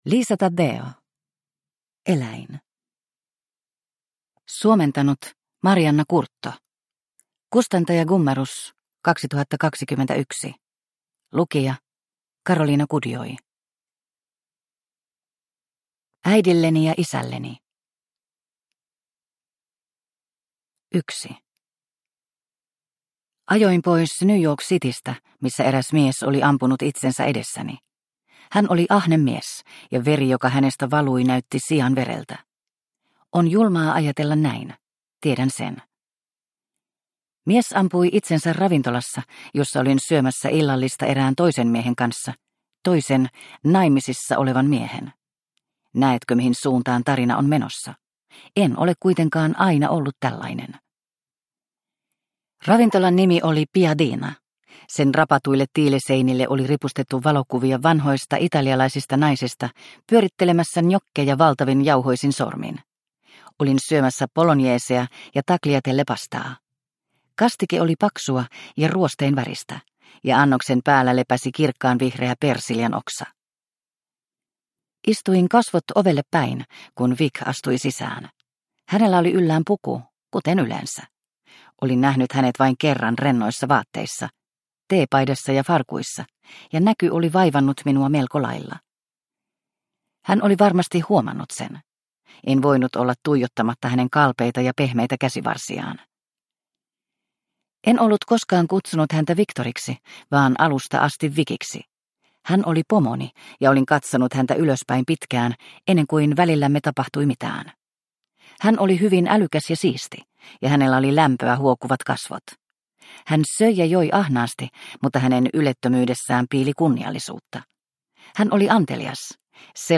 Eläin – Ljudbok – Laddas ner